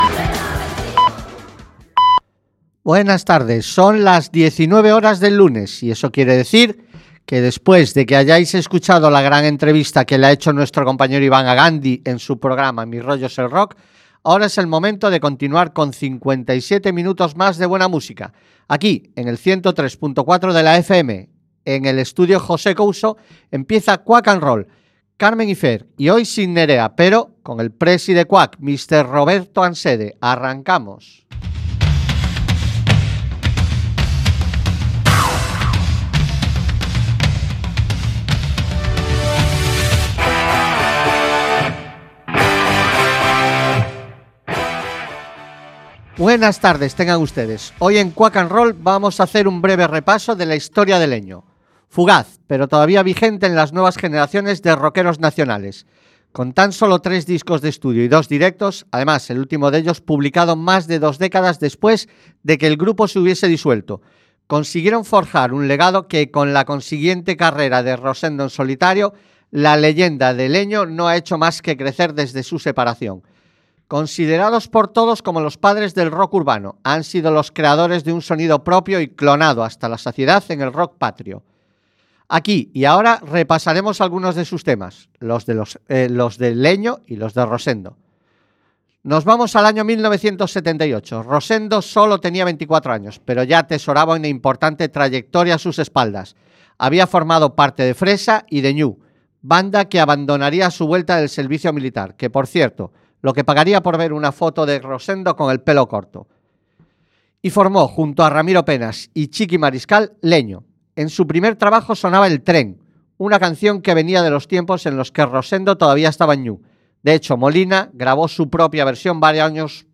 Programa musical que huye de las radioformulas y en el que podreis escuchar diversidad de generos... Rock , Blues , Country, Soul , Folk , Punk , Heavy Metal , AOR...